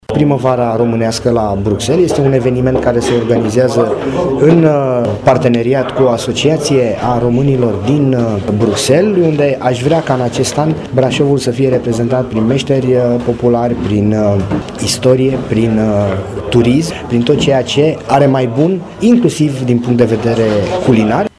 Într-o conferințe de presă acesta a făcut cunoscute câteva proiecte pe care le are în vedere, în ceea ce privește implicarea Brașovului și a brașovenilor în spațiul european.